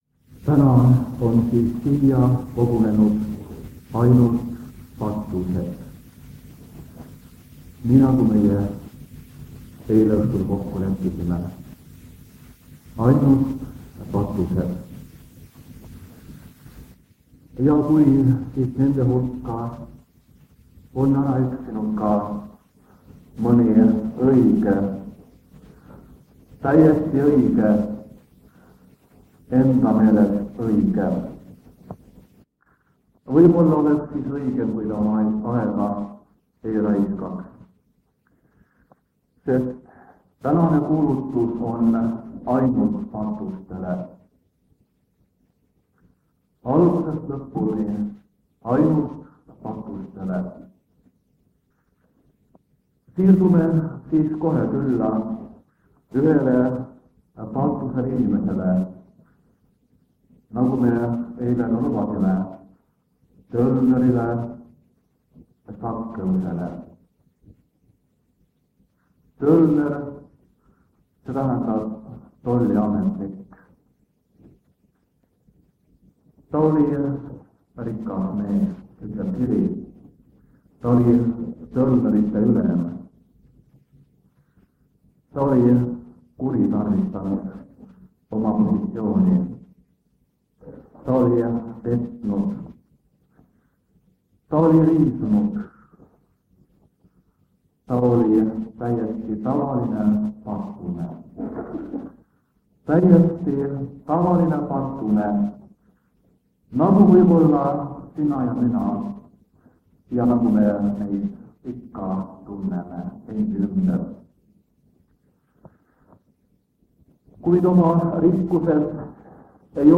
Jutluste miniseeria vanadelt lintmaki lintidelt 1974 aasta sügisel.